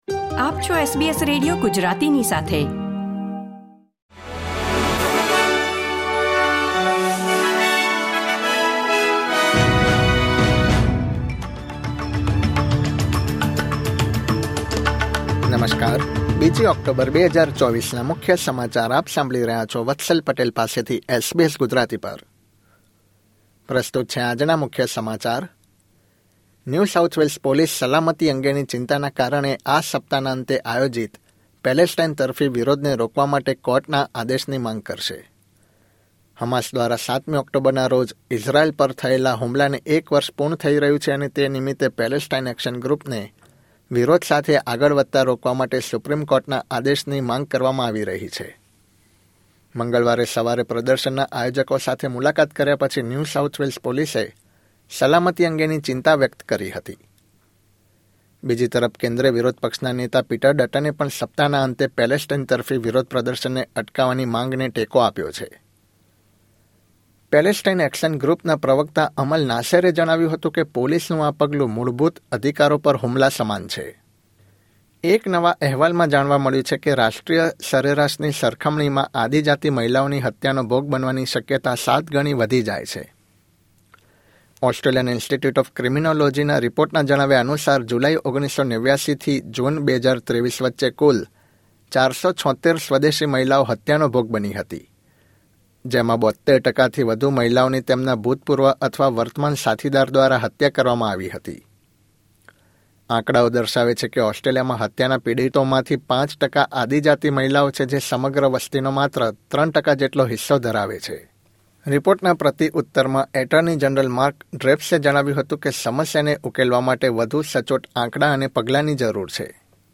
SBS Gujarati News Bulletin 2 October 2024